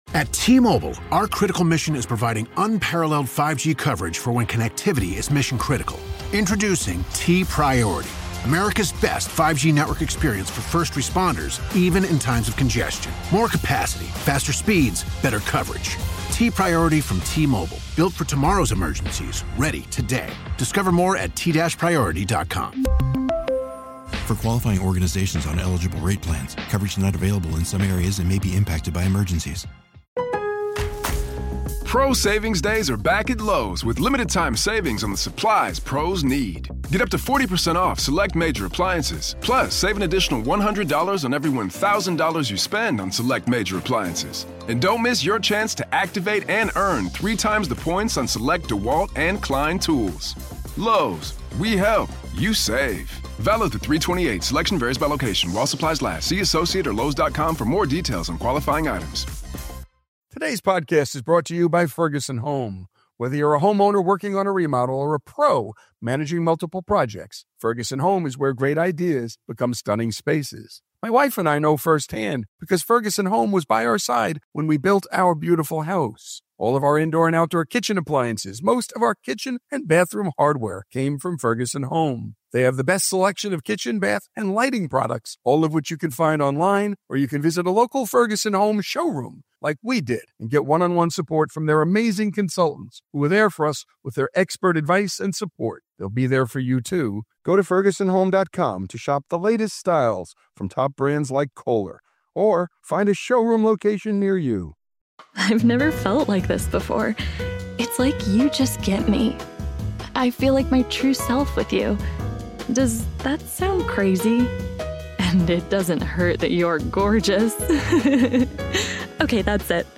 On this episode of Our American Stories, Walter Gretzky passed away at the age of 82, prompting an outpouring of tributes for a genuine, approachable, and authentic man who nurtured the unparalleled hockey talents of his son, Wayne Gretzky, on the family's famed backyard rink in Brantford, Ontario. The 60-year-old NHL legend, now an American citizen, spoke shortly after laying his father to rest.